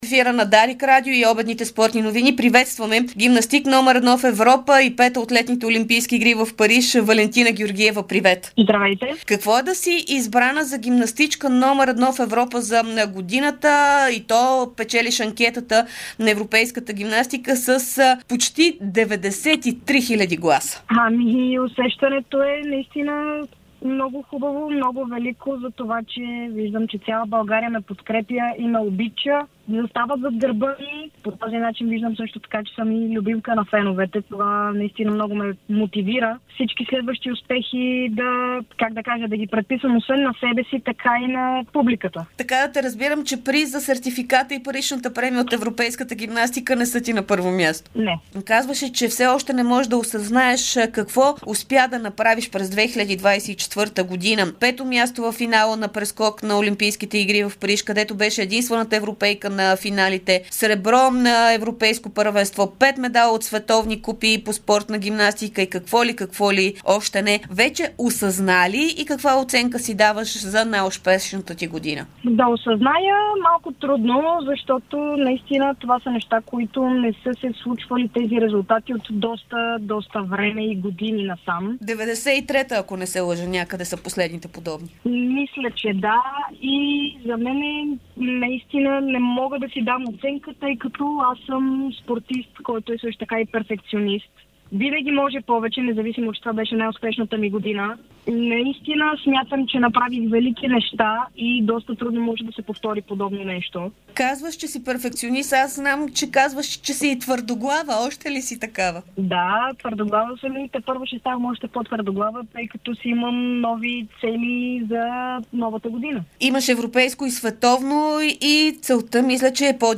Българската гимнастичка Валентина Георгиева даде ексклузивно интервю пред Дарик радио, след като преди дни бе определена за номер 1 в Европа за 2024 година.